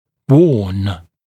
[wɔːn][уо:н]предупреждать, предостерегать